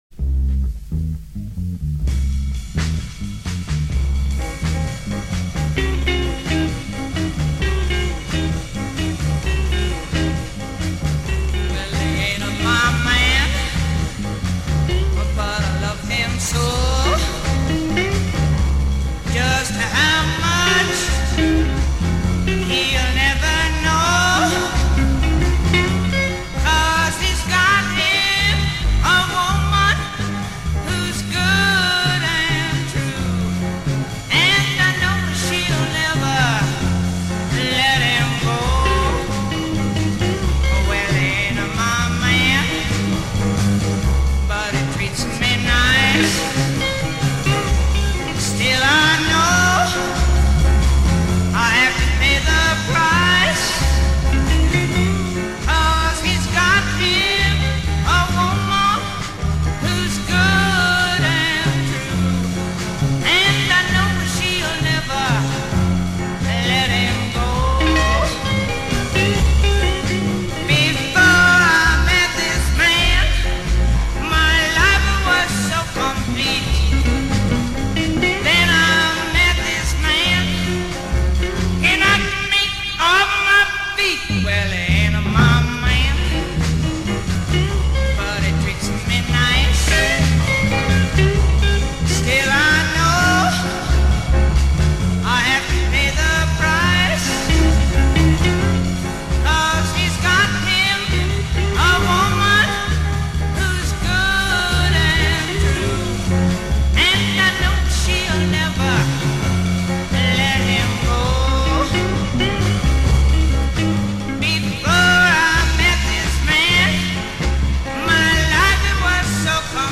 Transfer alert: speed correction poss. incorrect!